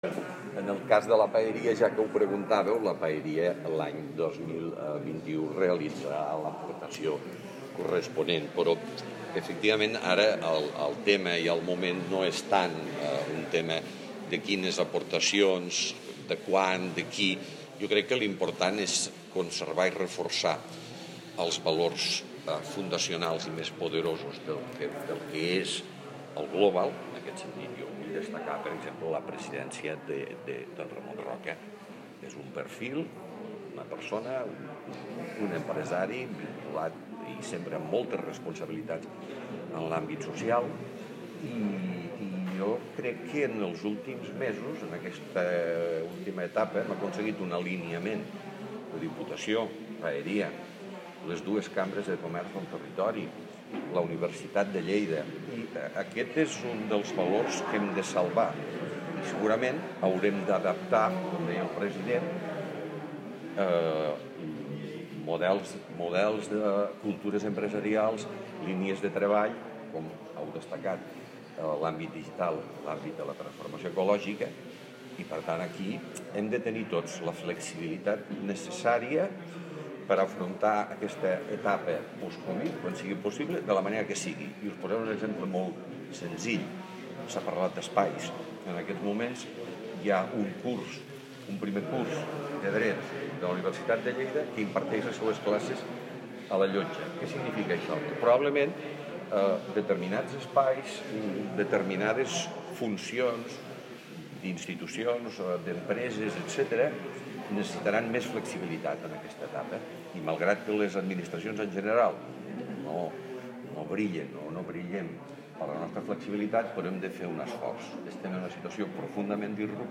tall-de-veu-de-lalcalde-de-lleida-miquel-pueyo-sobre-la-reformulacio-del-consorci-globalleida